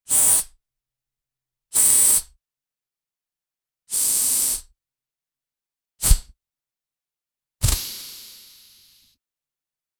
pneumatic-quick-release-f-twngq6ix.wav